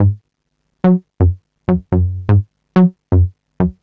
cch_bass_loop_korg_125_Gb.wav